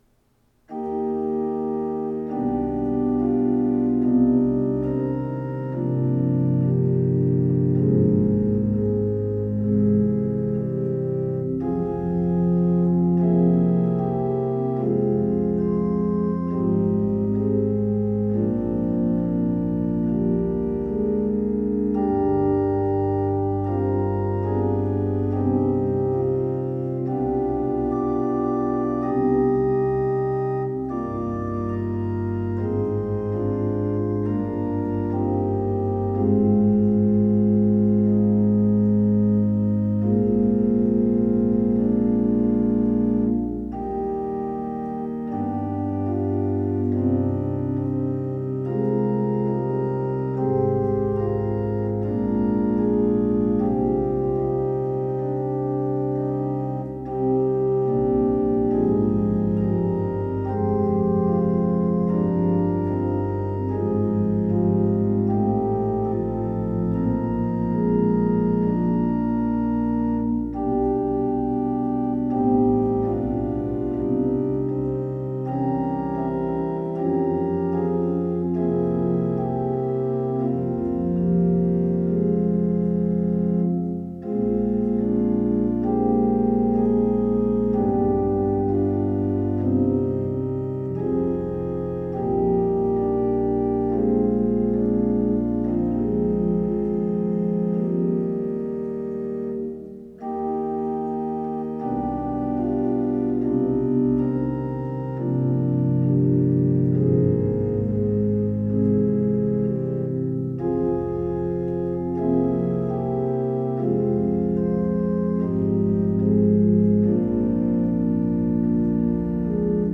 Abide With Me (Eventide) – The Organ Is Praise
I wrote this arrangement as a special musical number for a Saturday evening session of stake conference a couple of months ago.